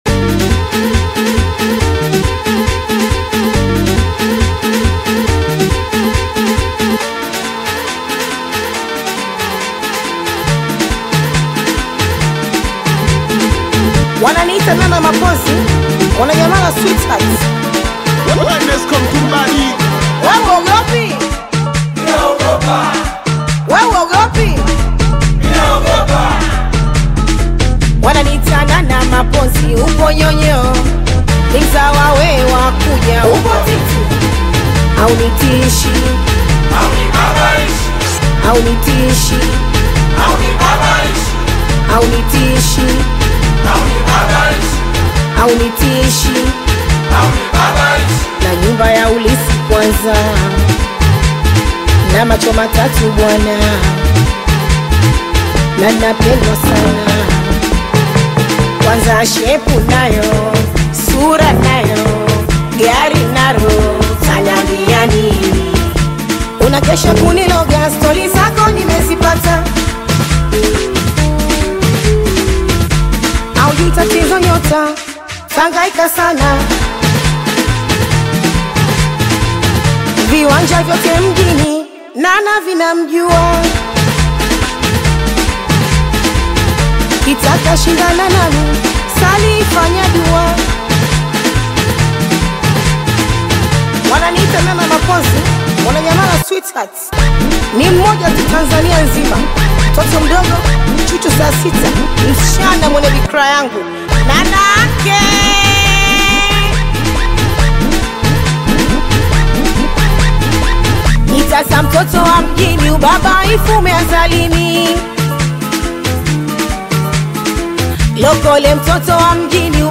Singeli music track
Singeli song